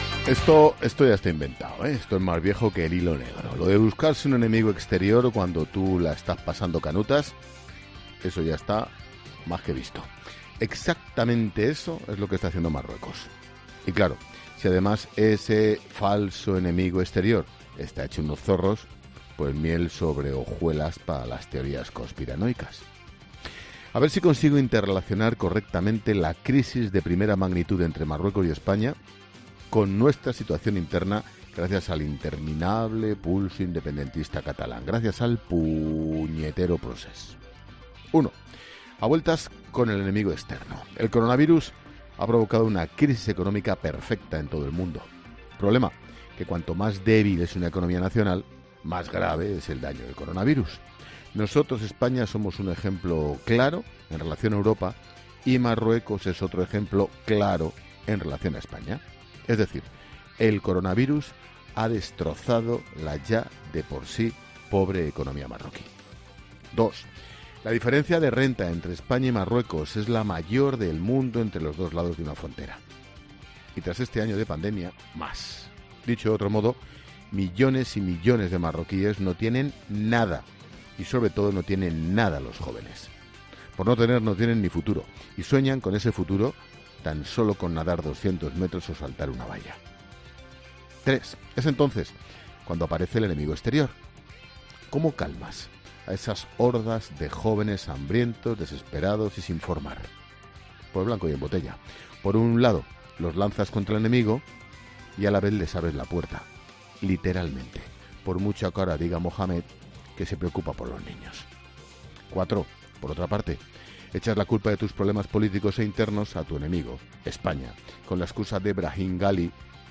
AUDIO: El director de 'La Linterna' habla en su monólogo sobre la crisis entre España y Marruecos